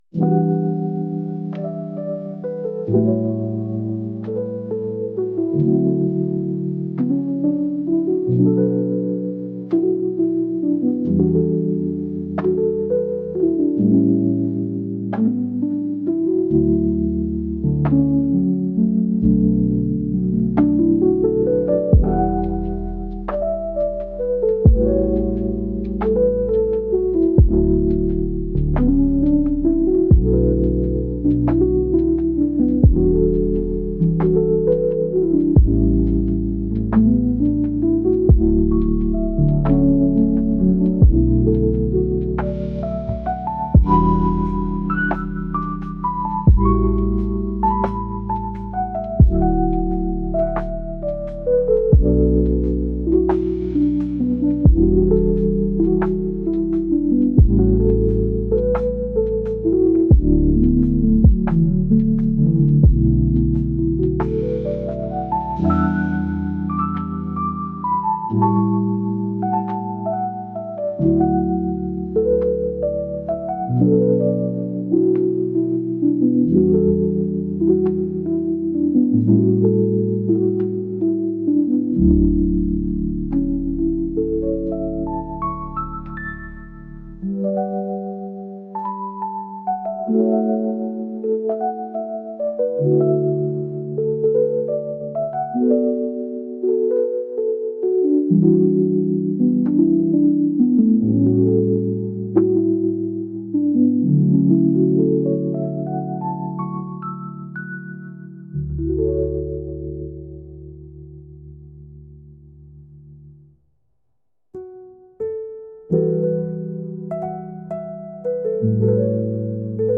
宿題の合間になんだかさぼってしまうようなのんびりした曲です。